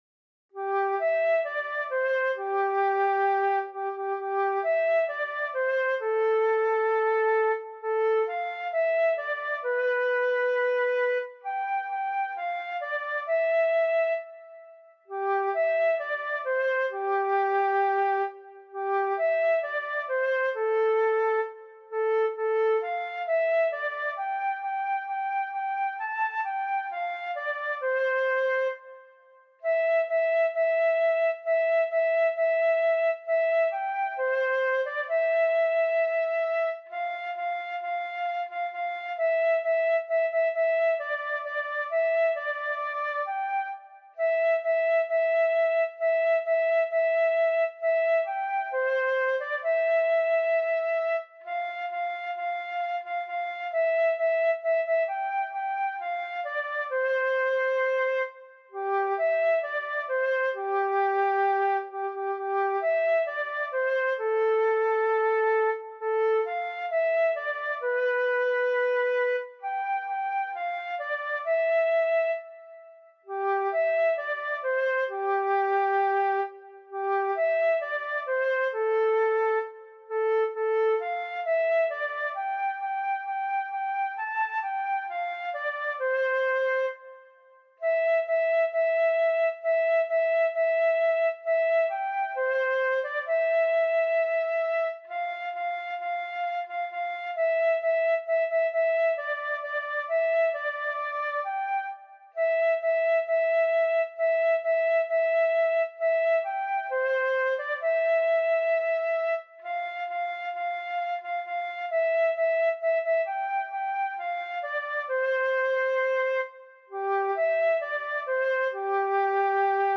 für Querflöte solo, Noten und Text als pdf, Audio als mp3